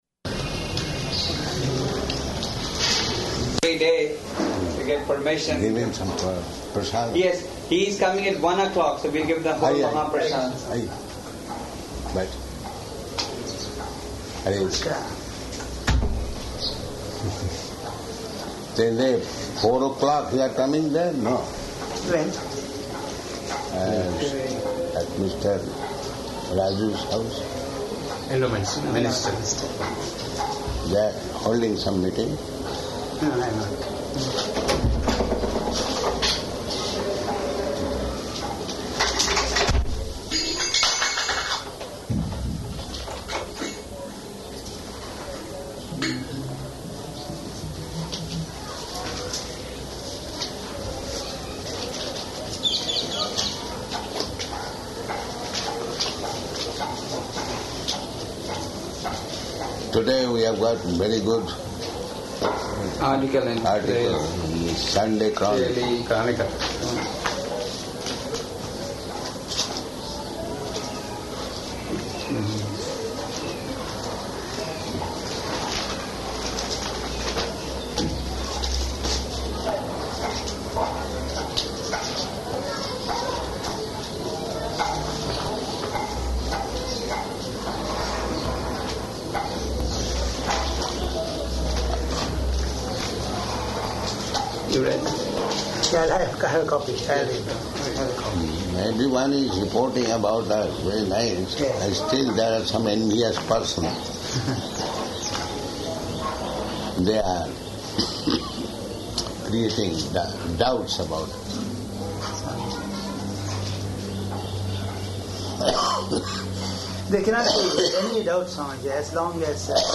Room Conversation with Endowments Commissioner of Andhra Pradesh
-- Type: Conversation Dated: August 22nd 1976 Location: Hyderabad Audio file